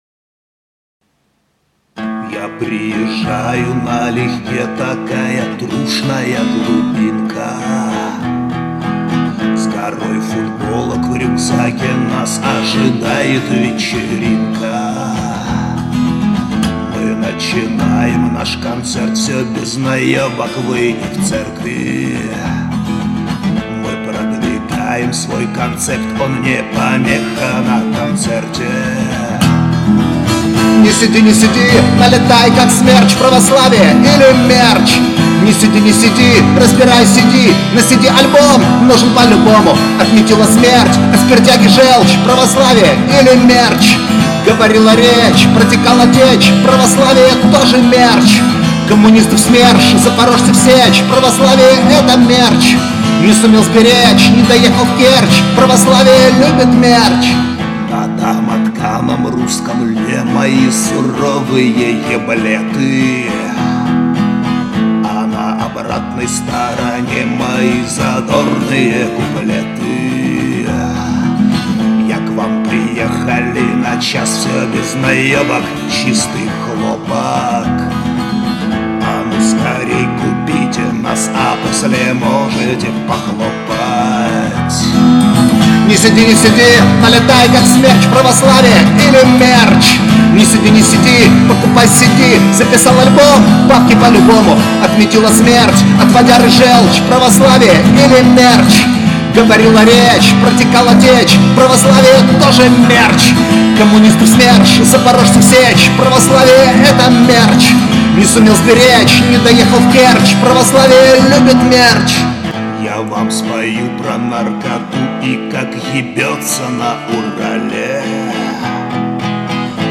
• Качество: 192 kbps, Stereo